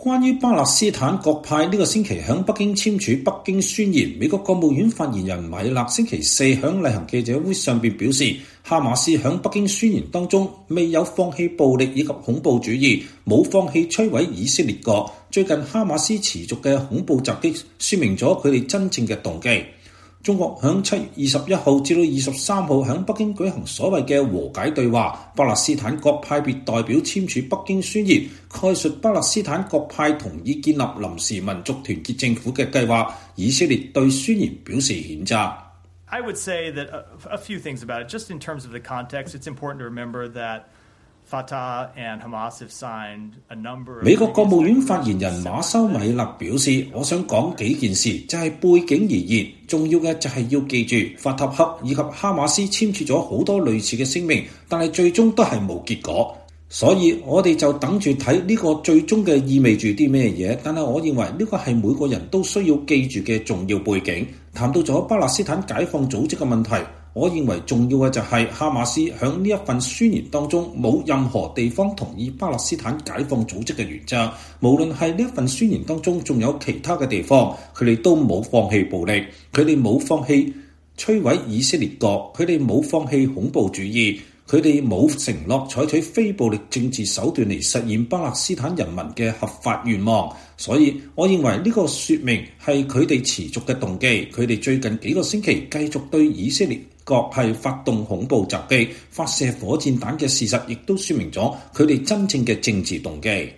關於巴勒斯坦各派本週在北京簽署《北京宣言》，美國國務院發言人米勒(Matthew Miller)在周四(7月25日)的例行記者會上表示，哈馬斯在《北京宣言》中沒有放棄暴力和恐怖主義，沒有放棄摧毀以色列國，最近哈馬斯持續的恐怖攻擊說明了他們真正的動機。